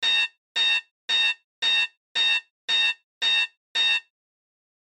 Wecktöne